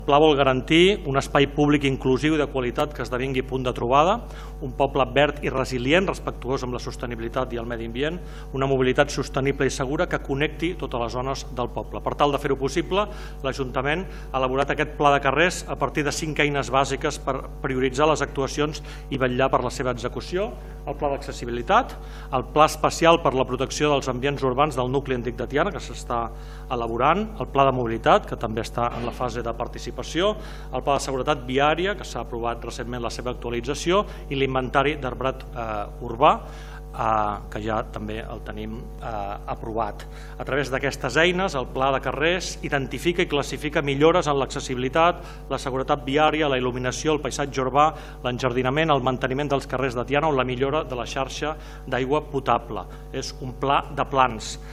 L’alcalde de Tiana, Isaac Salvatierra, va presentar en el ple d’aquest dimarts 4 de març el Pla de carrers, un “pla de plans” -mobilitat, accessibilitat, protecció del nucli antic, seguretat viària i arbrat públic- que té l’objectiu de revertir una falta d’inversió crònica en la millora dels carrers, places, parcs i voreres: